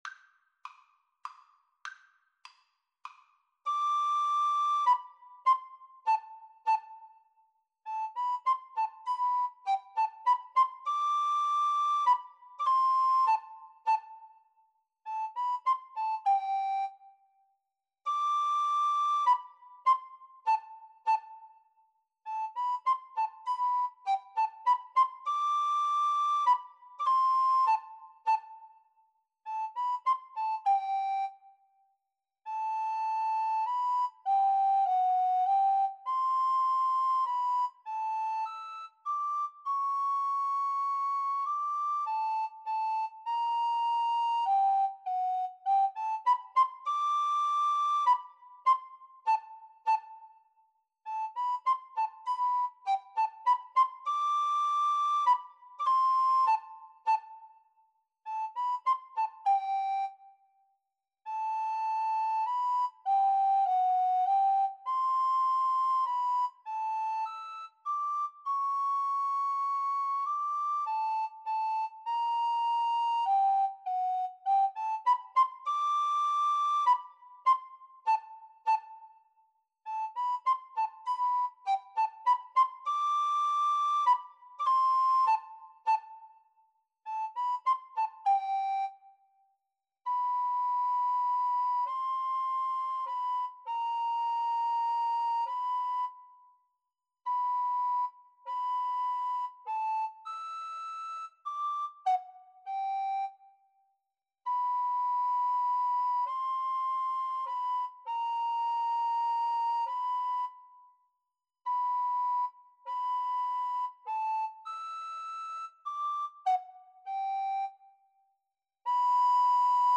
3/4 (View more 3/4 Music)
Allegretto - Menuetto
Classical (View more Classical Alto Recorder Duet Music)